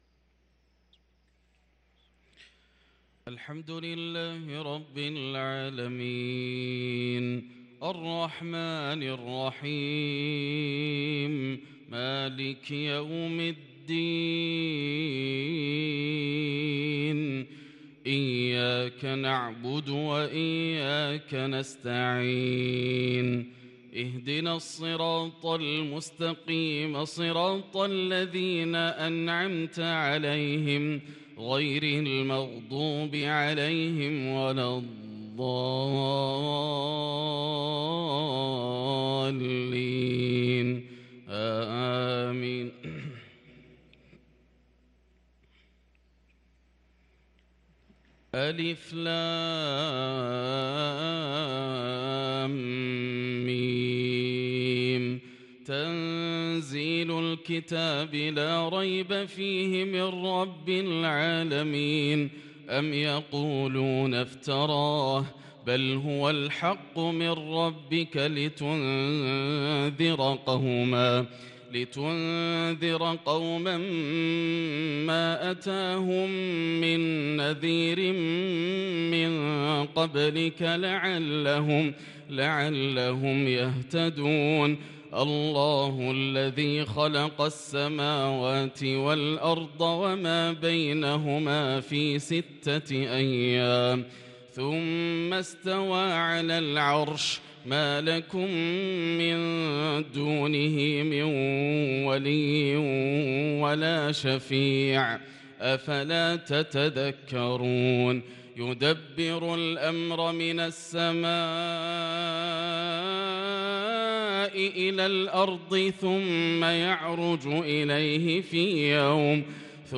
صلاة الفجر للقارئ ياسر الدوسري 4 ربيع الأول 1444 هـ
تِلَاوَات الْحَرَمَيْن .